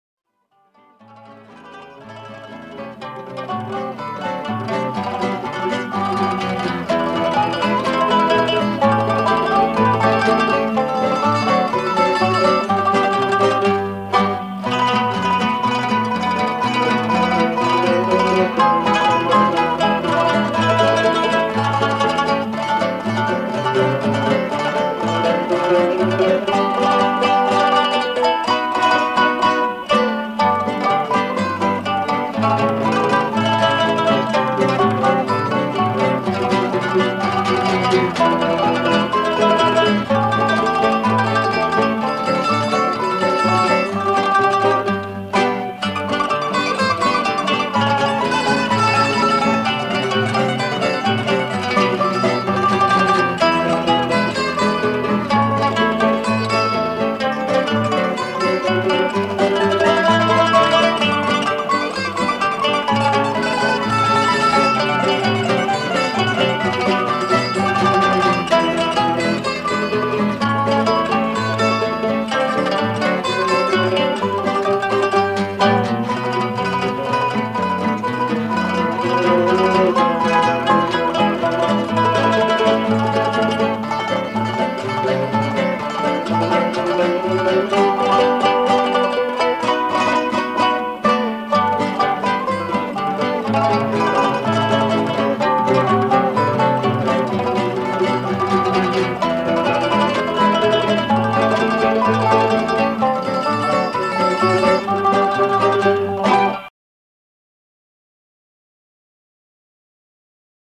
primo mandolino.
mandola
chitarra bolognese.